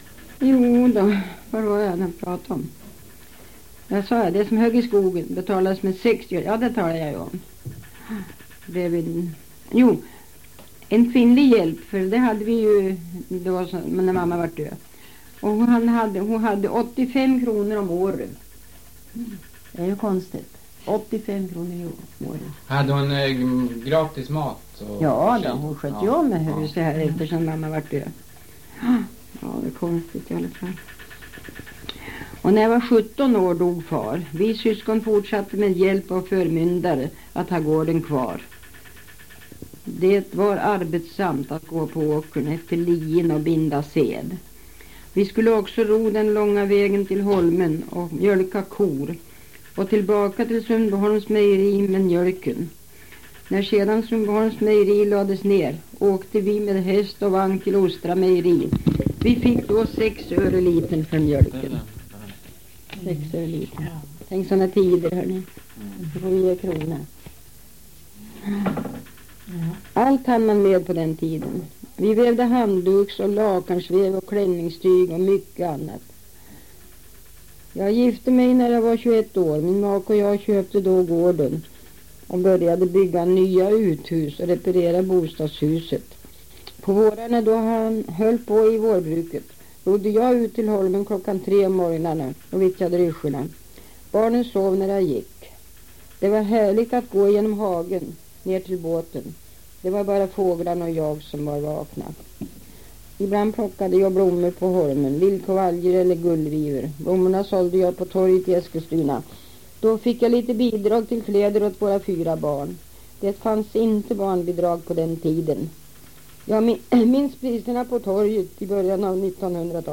Två moraprofiler ljudupptagning.
Grundmaterialet är ett par vanliga ljudkassetter med tillhörande brus….